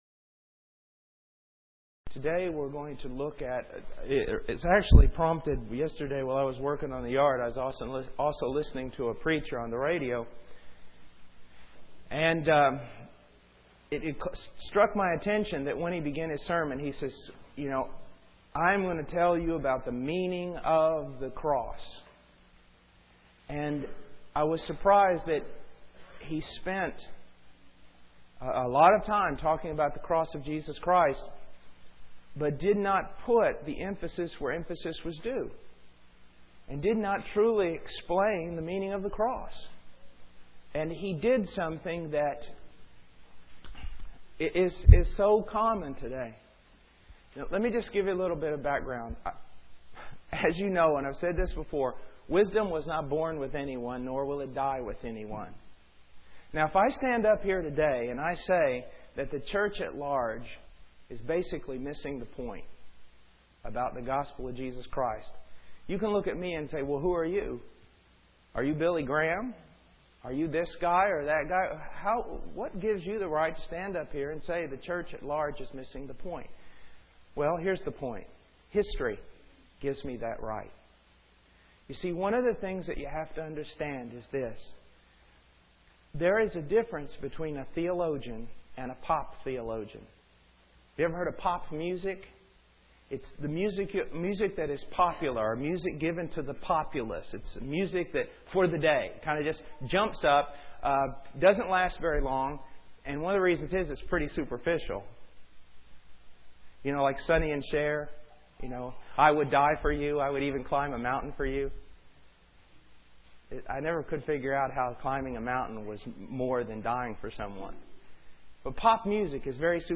In this sermon, the speaker begins by asserting his right to critique the church's understanding of the gospel based on historical context. He distinguishes between theologians and 'pop theologians,' comparing the latter to pop music that is superficial and short-lived. The speaker then criticizes a preacher he heard on the radio who discussed the cross of Jesus Christ without emphasizing its true meaning.